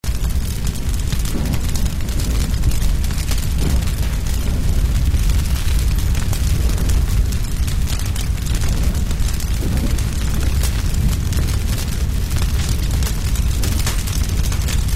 Fire Sound Effect